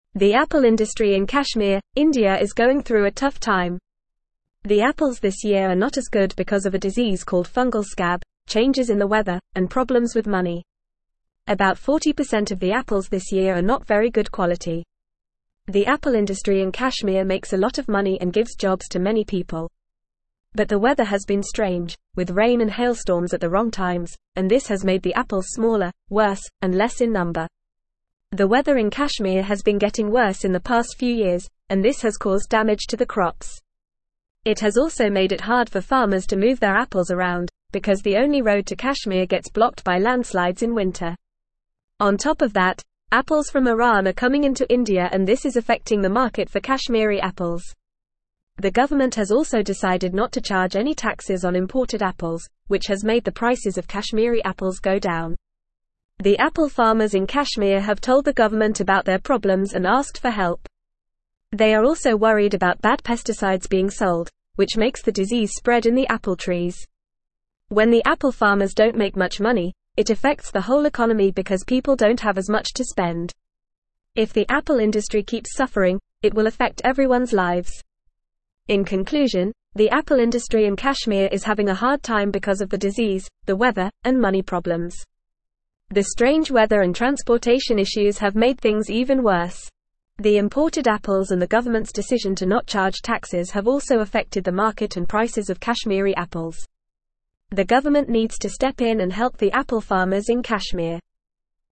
Fast
English-Newsroom-Upper-Intermediate-FAST-Reading-Crisis-in-Kashmirs-Apple-Industry-Challenges-and-Implications.mp3